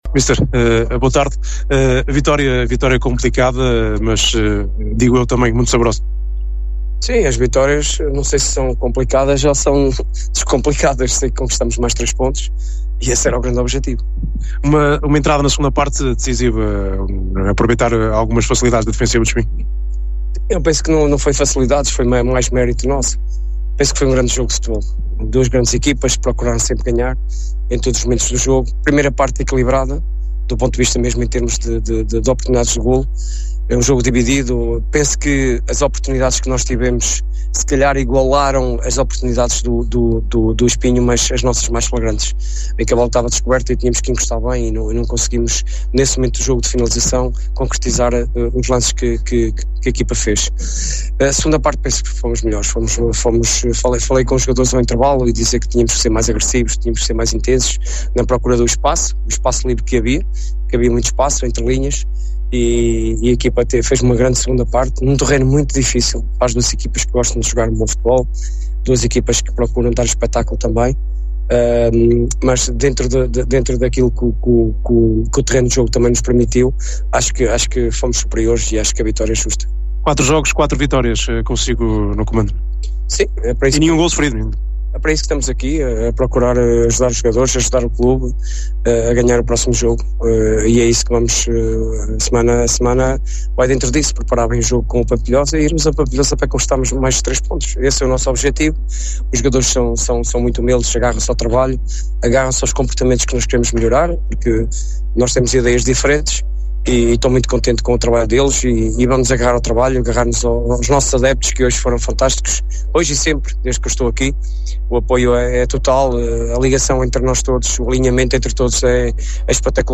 acompanhou o embate no Comendador Henrique Amorim e ouviu os técnicos no final.